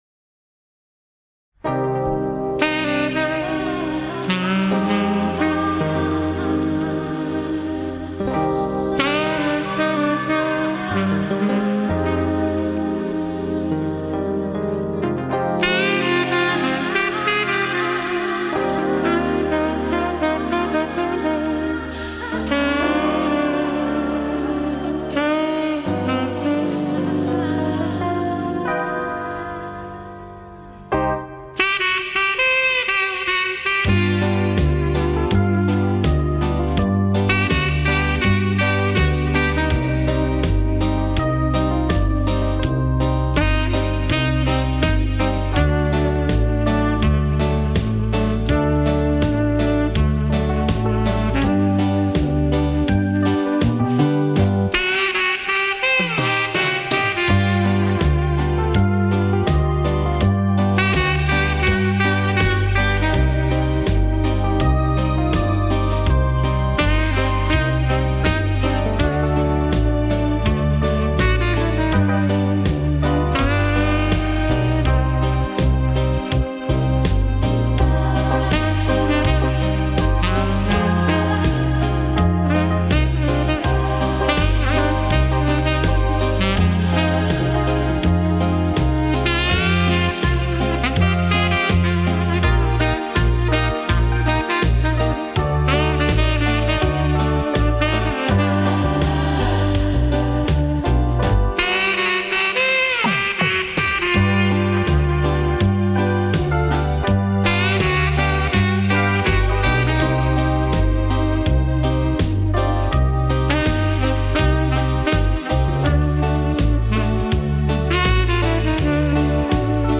Но запись достаточно чистая.